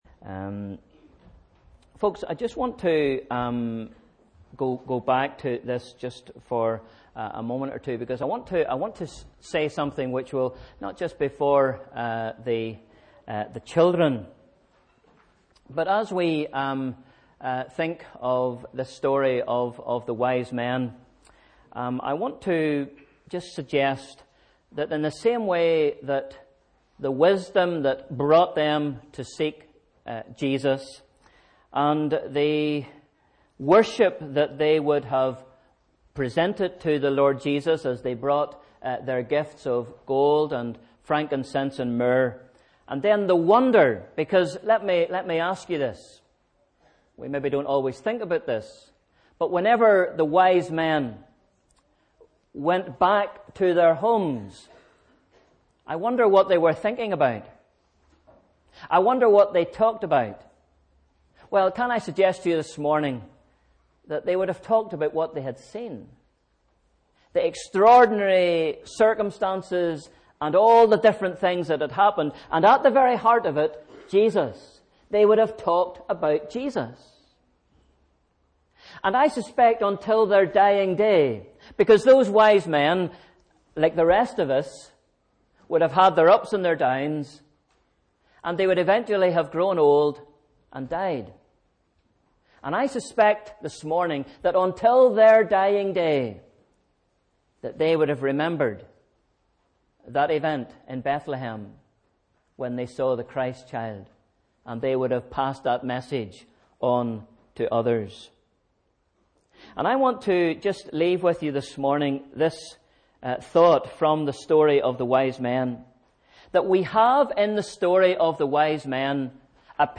Sunday 20th December 2015 – Morning Service